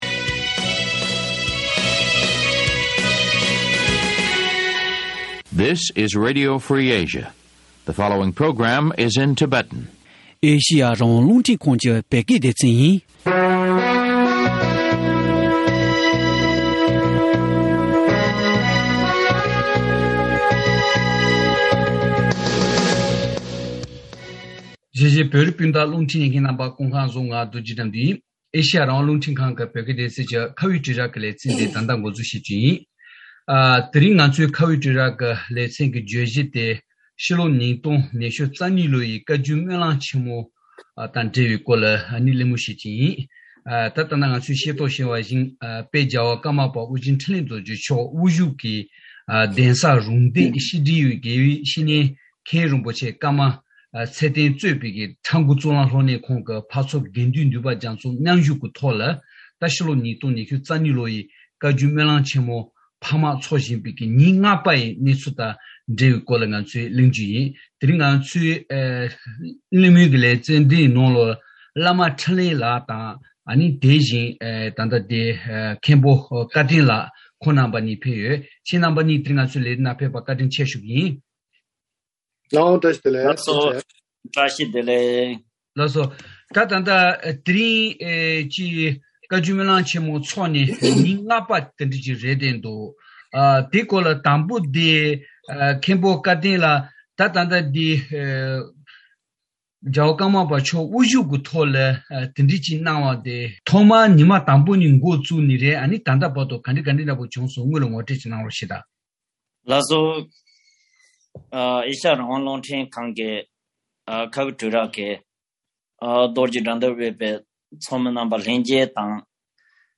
བཀའ་མོལ་ཞུས་པར་གསན་རོགས་ཞུ།།